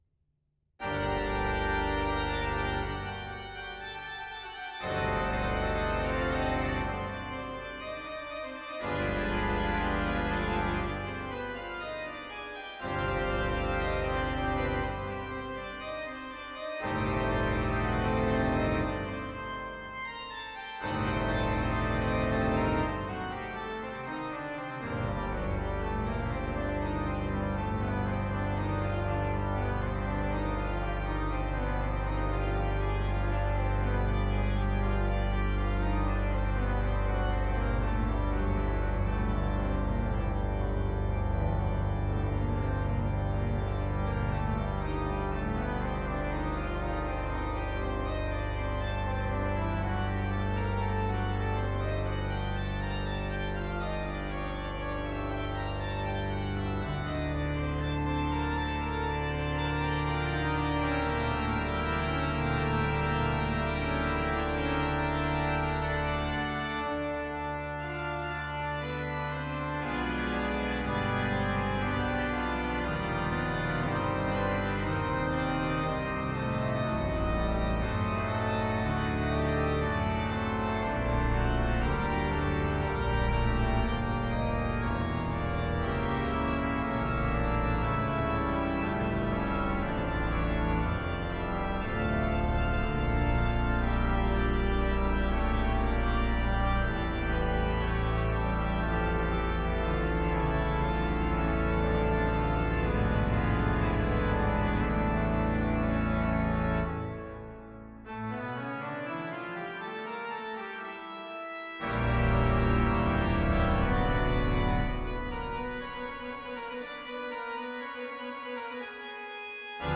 Organ Classical
Bach wrote hundreds of pieces for organ, choir, as well as many other instruments.
fantasia-and-fugue-in-g-minor-bwv-542.mp3